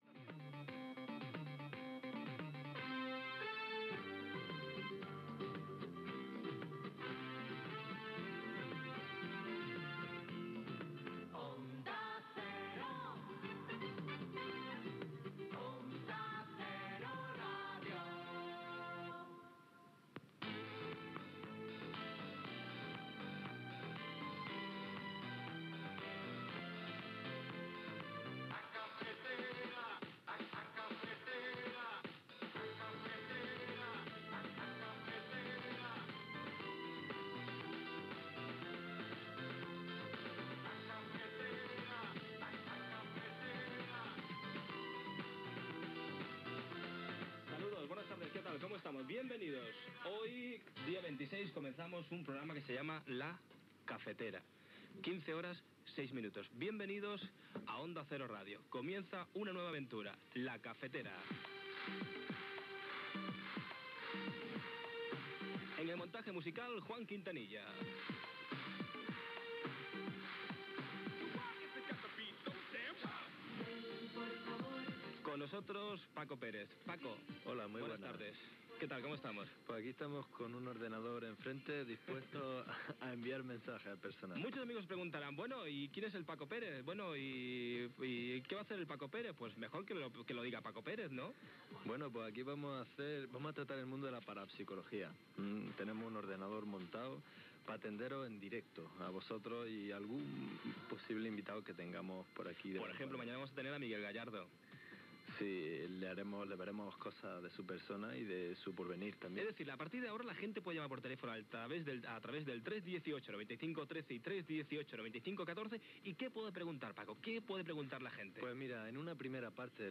Indicatiu de l'emissora, careta del programa, data, salutació, anunci de la possibilitat de fer consultes astrològiques, telèfon de participació
Entreteniment
FM